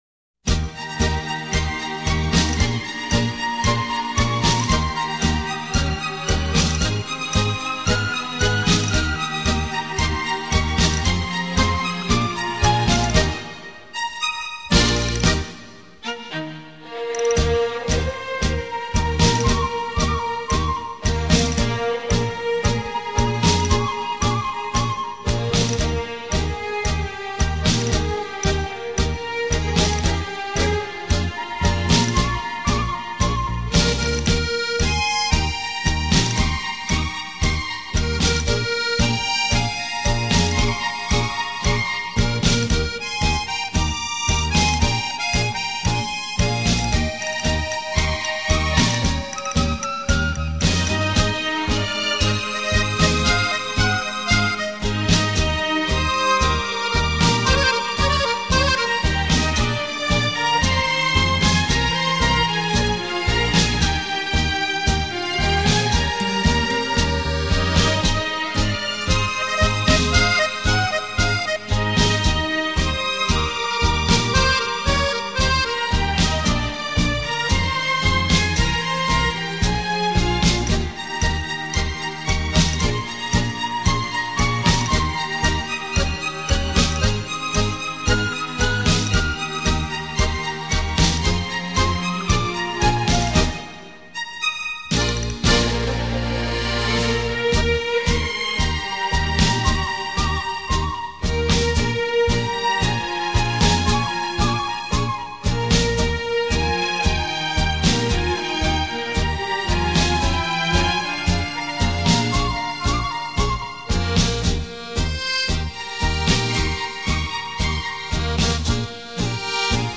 重新诠释并融入舞曲风格保证令人耳目一新